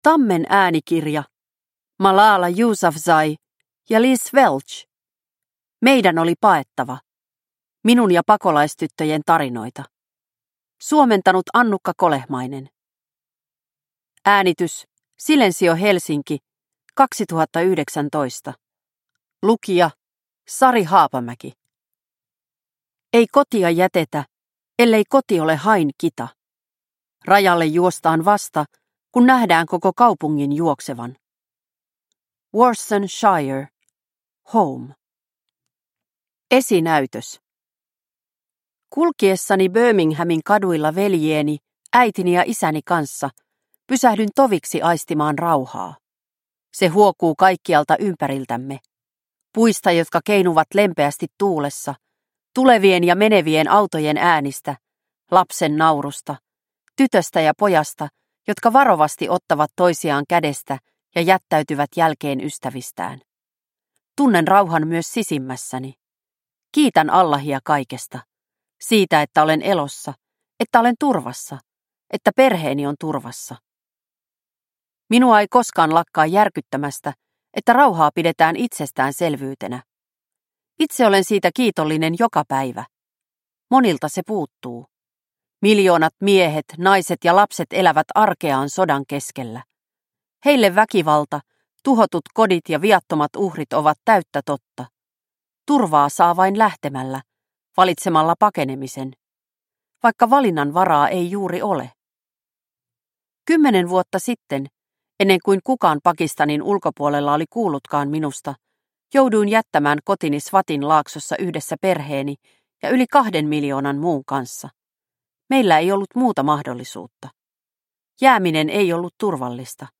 Meidän oli paettava – Ljudbok – Laddas ner